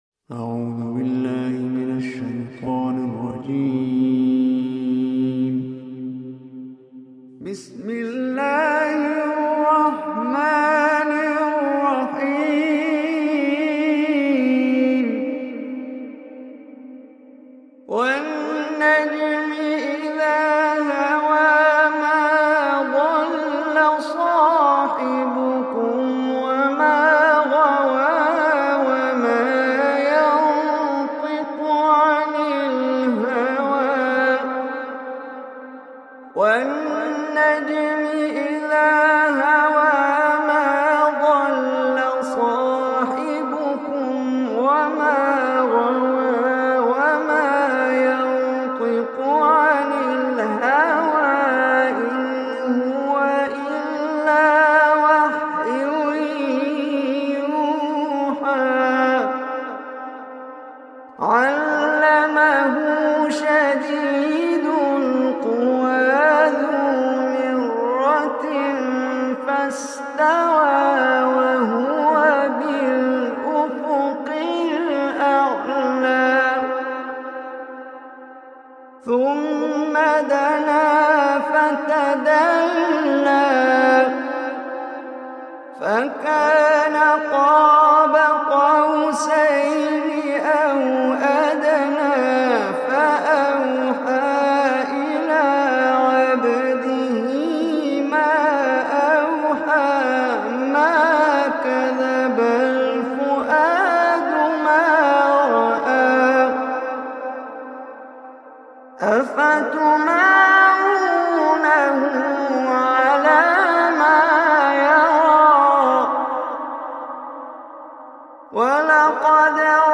Surah An-Najm Recitation by Omar Hisham Al Arabi
Surah An-Najm, listen online or play quran recitation in the beautiful voice of Omar Hisham Al Arabi.